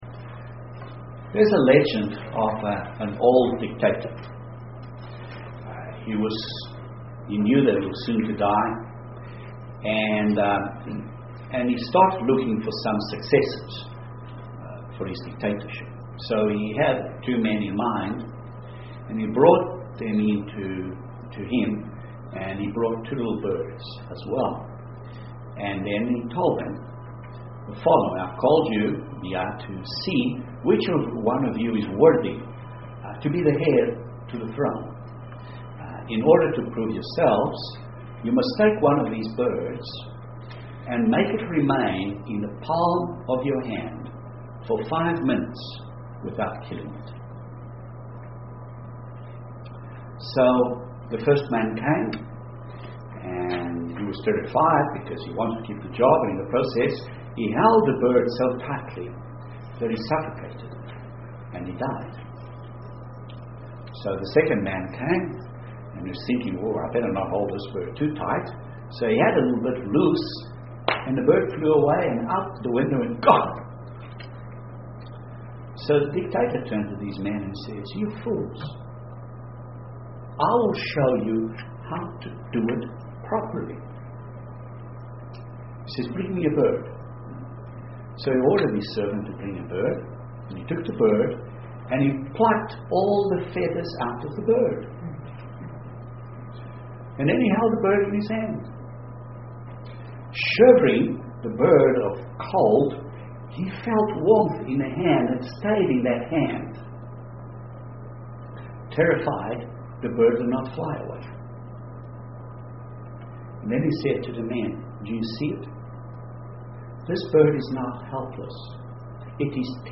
Satan instigates fear to control, but God gives us a spirit of power, love and a sound mind UCG Sermon Transcript This transcript was generated by AI and may contain errors.